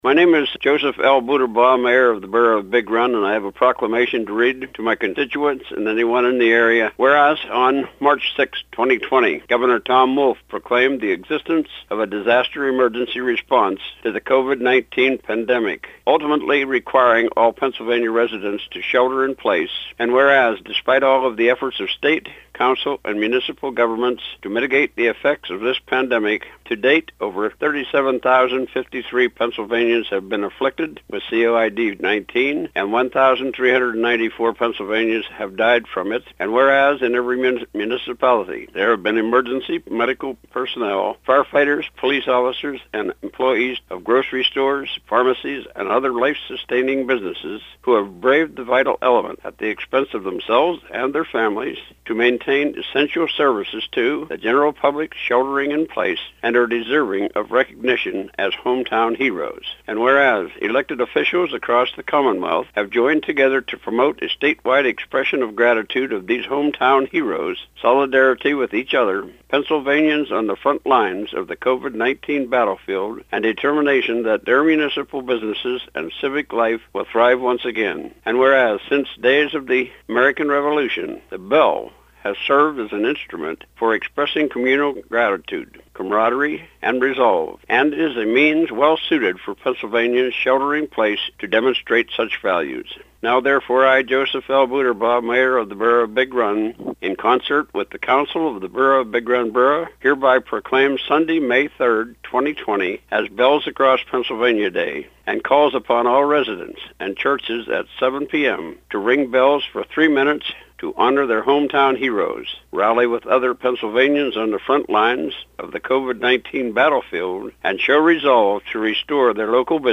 Punxsutawney mayor Richard Alexander and Big Run mayor Joseph L. Butebaugh issued proclamations about the special day.
Big-Run-Mayor.mp3